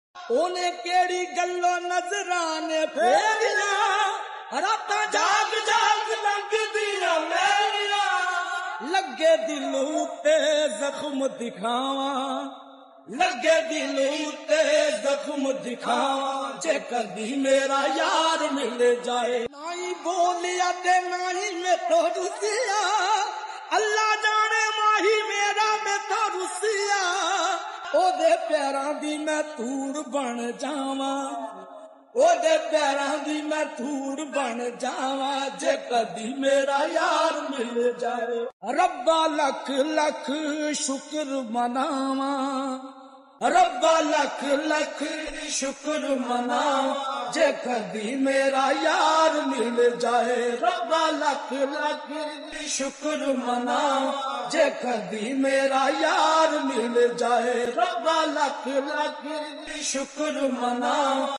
Full Slowed And Reverb